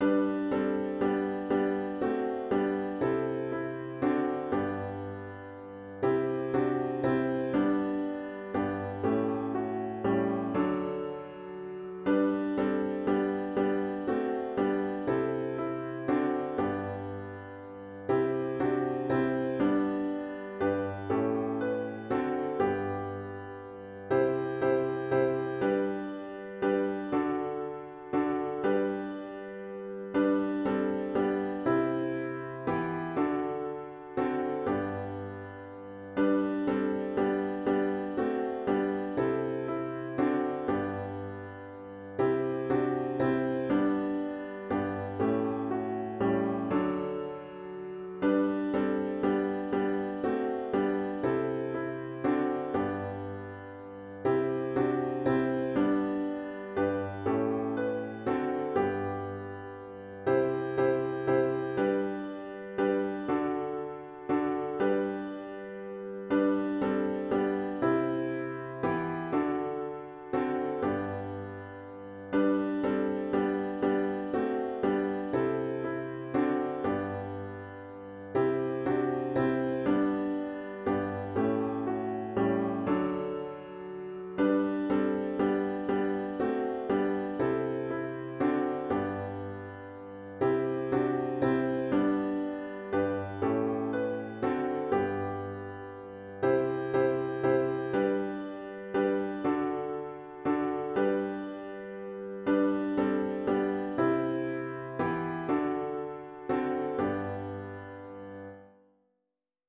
CLOSING HYMN   “Jesus, Thy Boundless Love to Me”   GtG 703